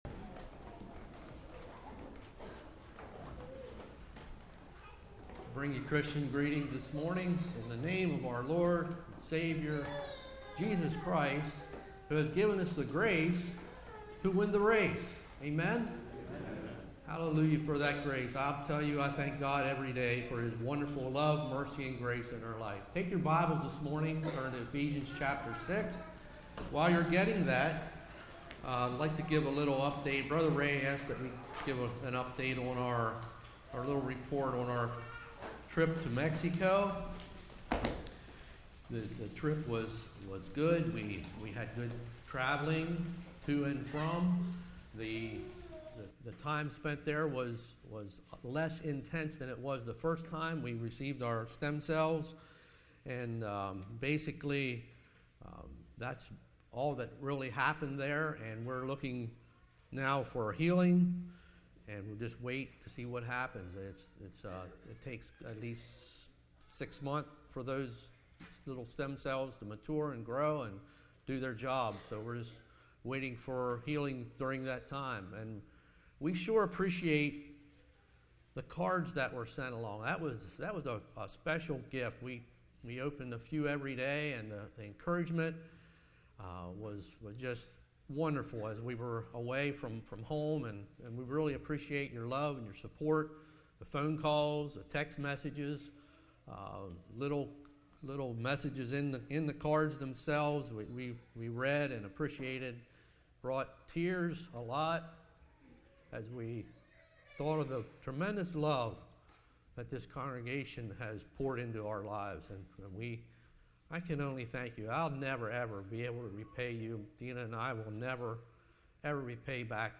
Ephesians 6:10-19 Service Type: Morning John the Baptist Spiritual warriors Armor of God « What Time Is It?